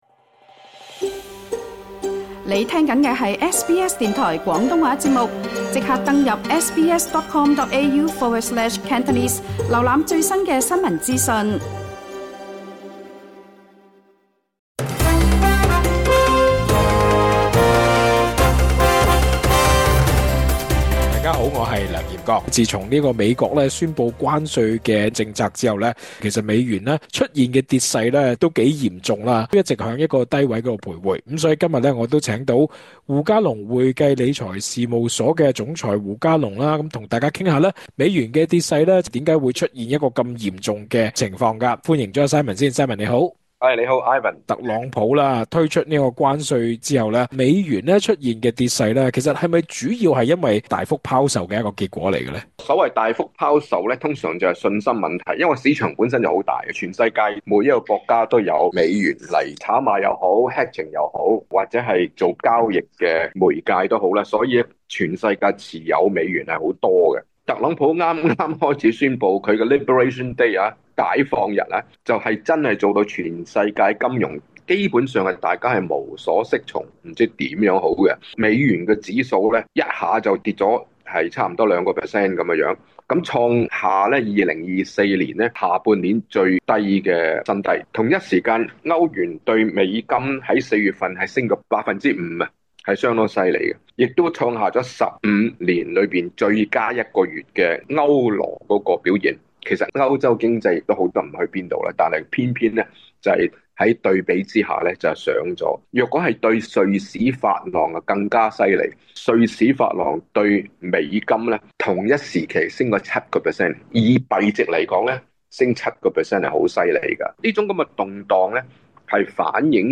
接受SBS廣東話節目【寰宇金融】訪問時，認為即使關稅的力度減弱，但美元急跌後未必容易馬上能作出反彈。